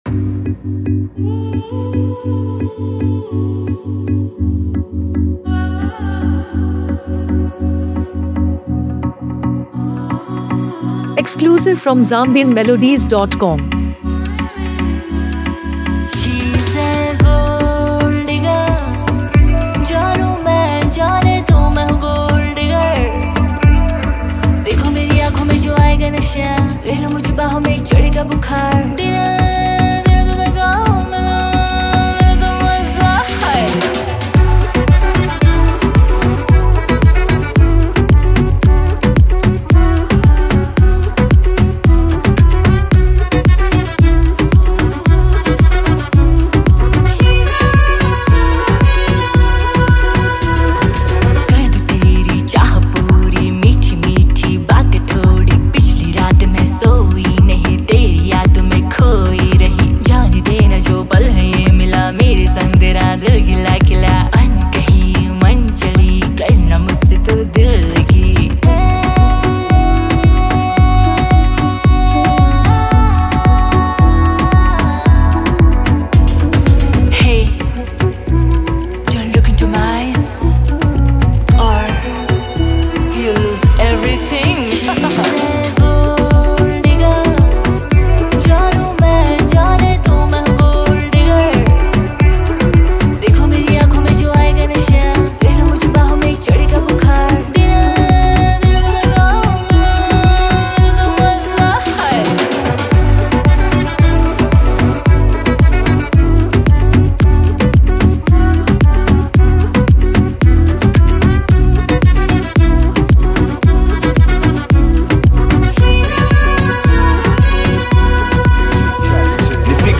hip-hop heavyweight
known for her soulful voice and global appeal
With a catchy hook and engaging verses